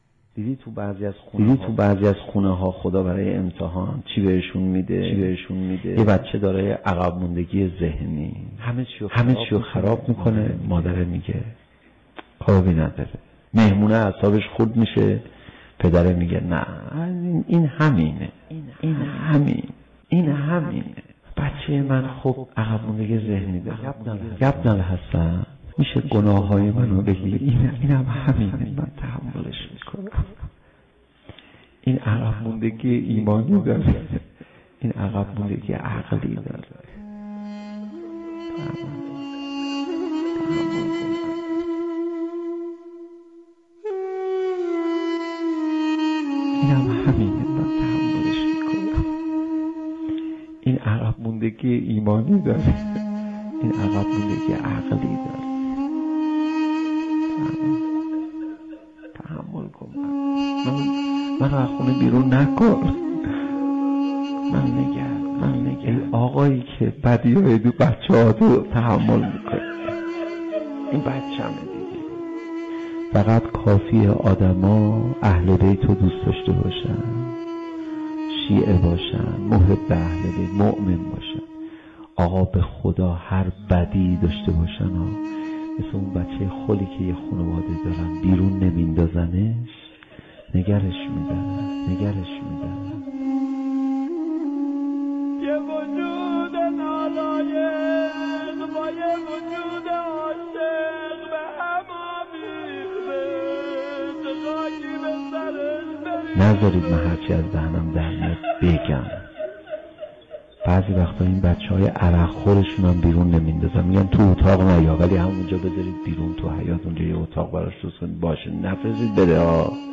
سخنرانی تاثیرگذار